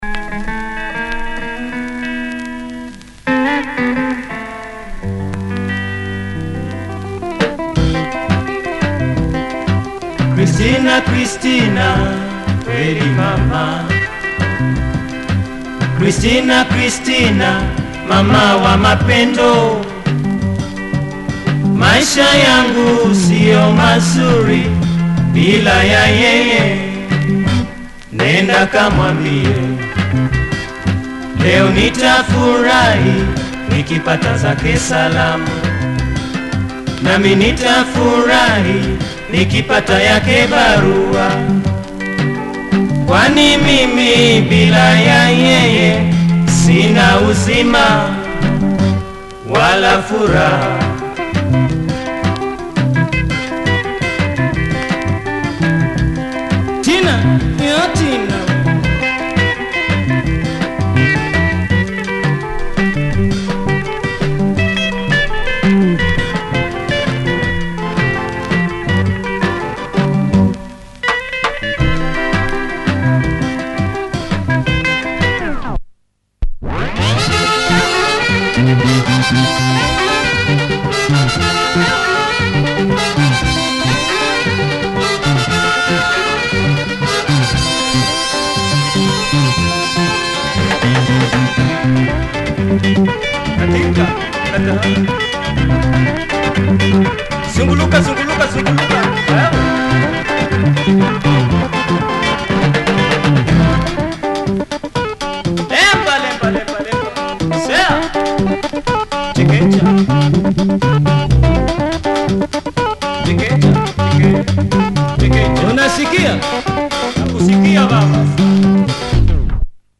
massive bass and great percussive elements.